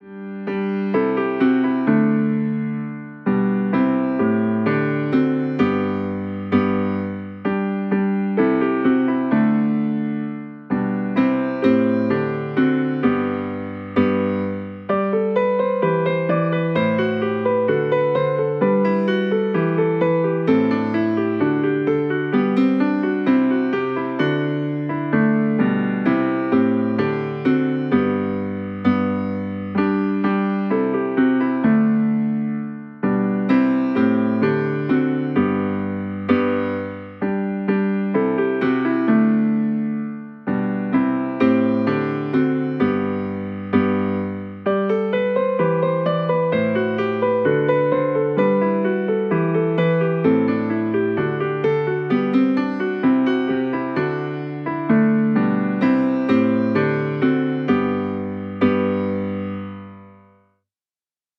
chants traditionnels
interprétés dans une atmosphère chaleureuse et raffinée.